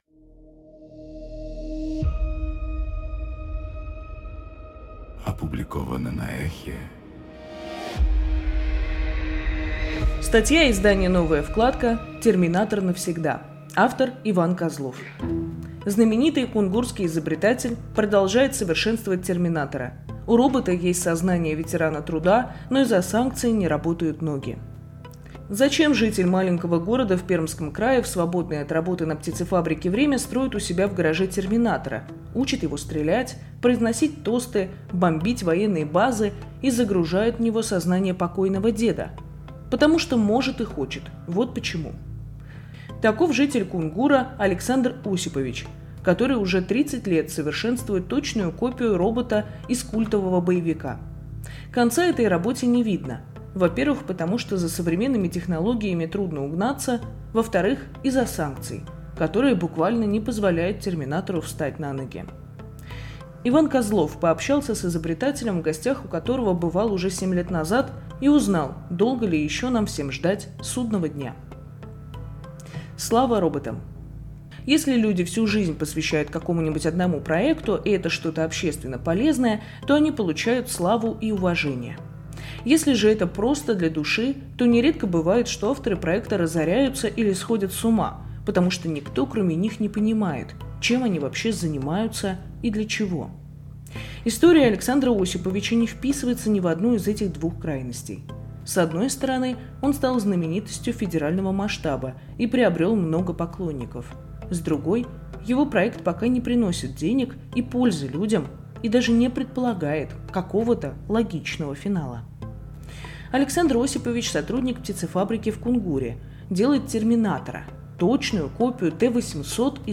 Статья издания «Новая вкладка».